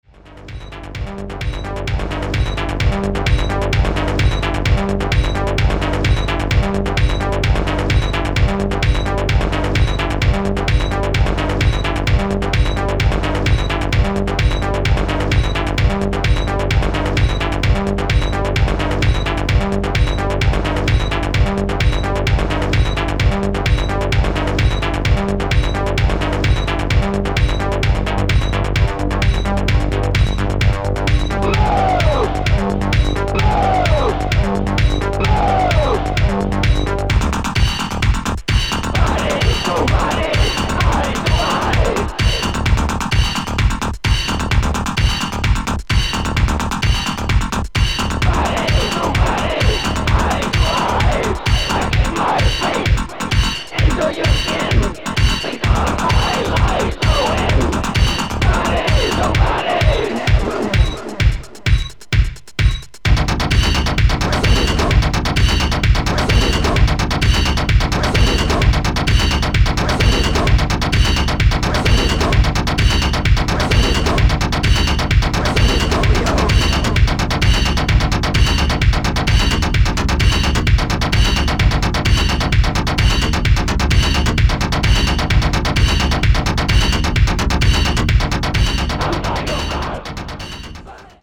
techno industrial re-edit